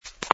sfx_fturn_female03.wav